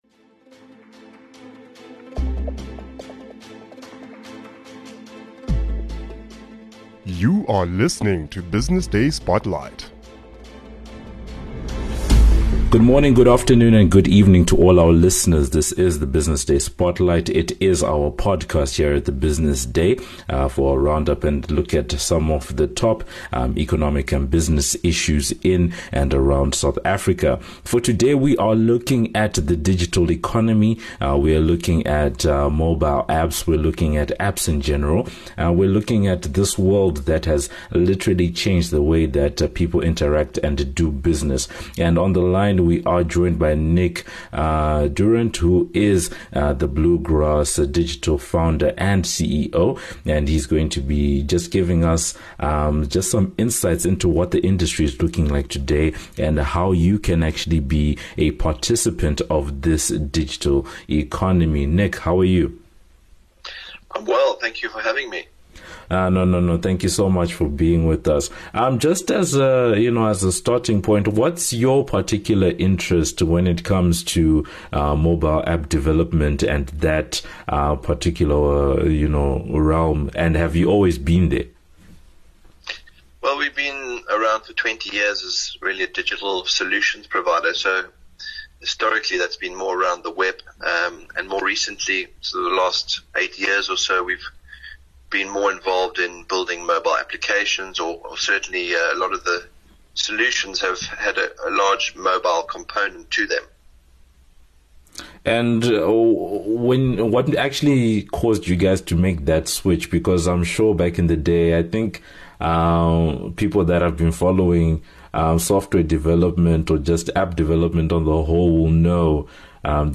Listen in to hear the full discussion, together with thoughts around these and other questions.